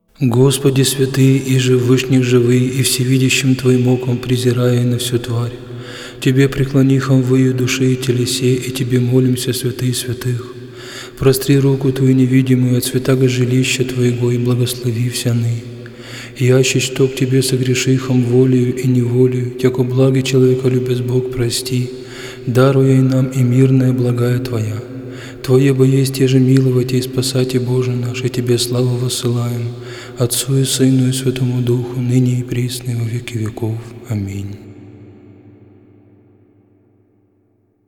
Псалмы царя-пророка Давида Диск 4  3 Прослушай всички композиции от този стил музика Прослушай всички композиции от този стил музика в случаен ред Проповед
03-Молитва.mp3